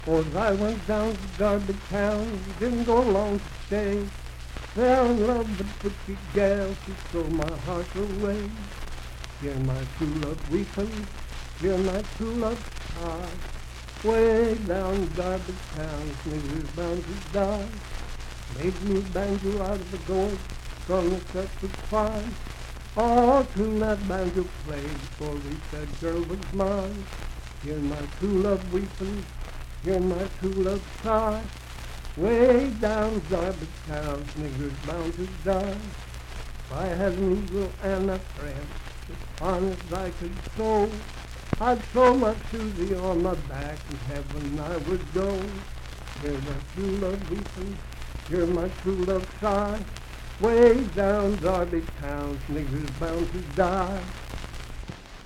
Unaccompanied vocal music
Verse-refrain 3(4) & R(4).
Voice (sung)
Grant County (W. Va.)